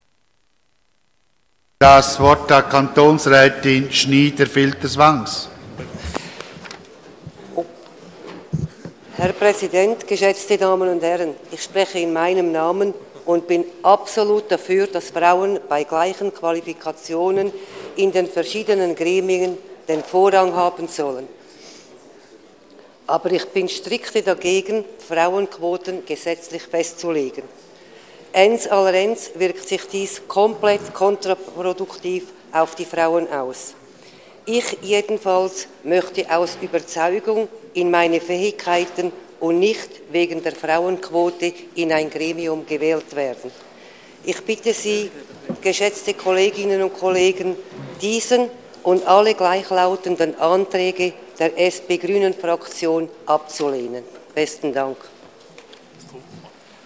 Session des Kantonsrates vom 23. bis 25. Februar 2015